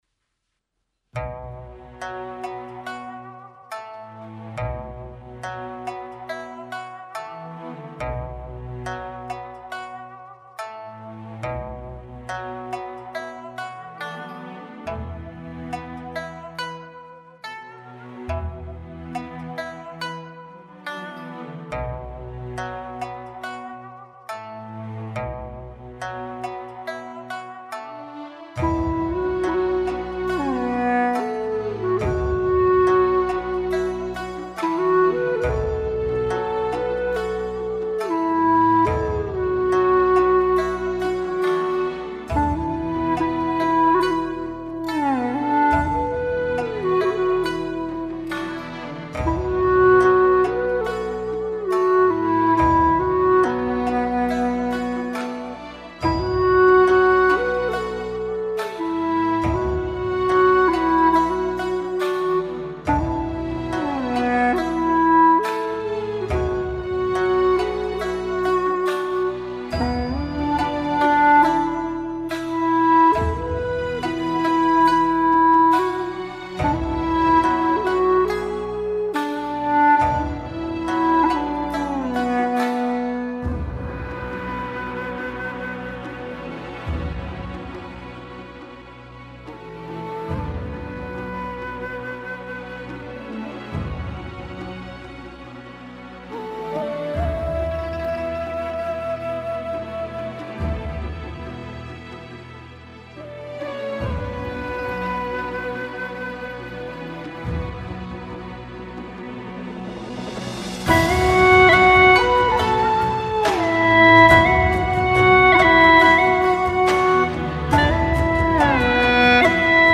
调式 : D 曲类 : 古风